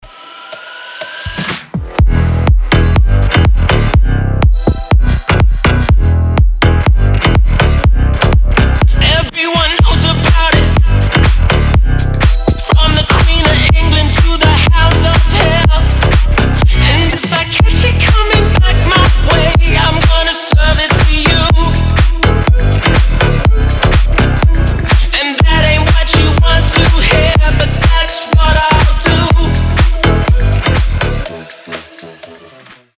• Качество: 320, Stereo
EDM
мощные басы
Brazilian bass